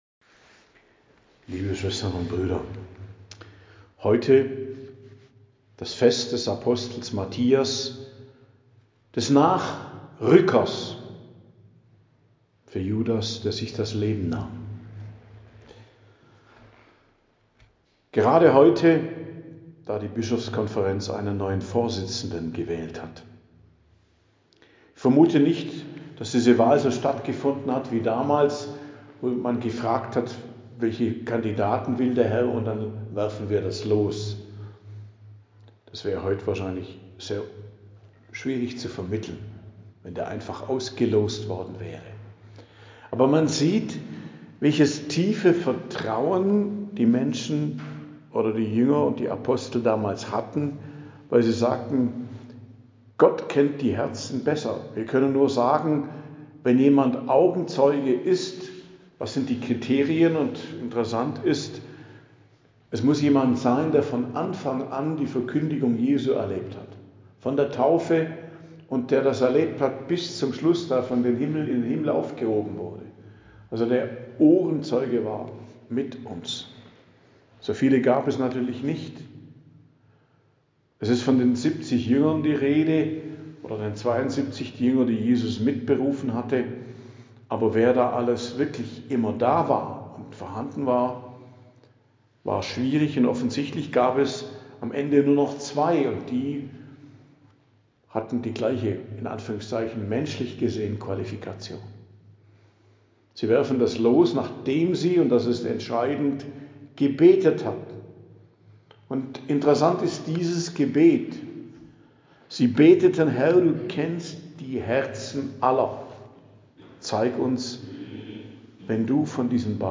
Predigt am Fest des Hl. Matthias, Apostel, 24.02.2026